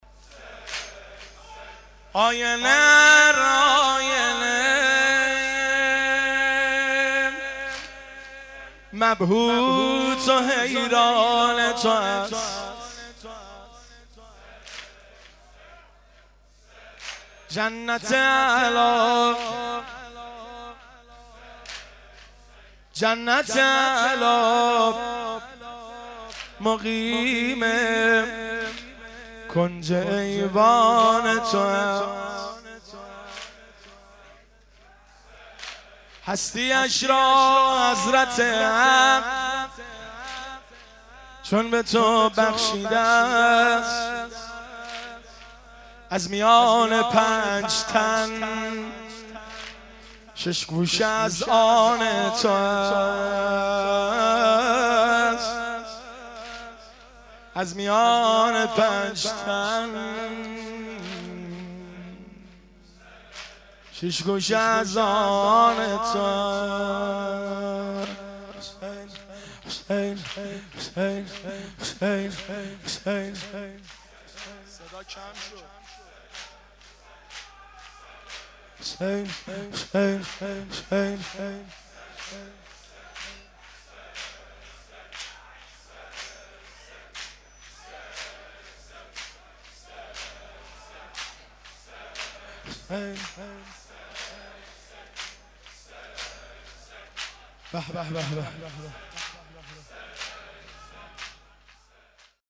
• زمزمه - شب 4 محرم 1390 هیئت محبان الحسین تهران